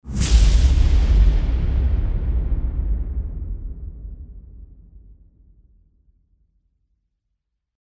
FX-237-IMPACT
FX-237-IMPACT.mp3